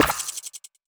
Twisted Mech Notification3.wav